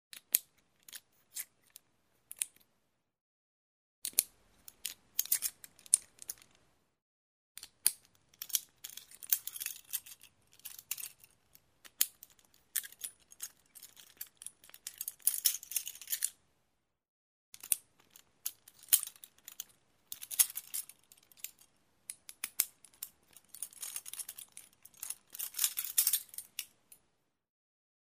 На этой странице собраны различные звуки наручников: от звонкого удара металла до характерного щелчка замка.
Звук отпирания наручников ключом, освобождение рук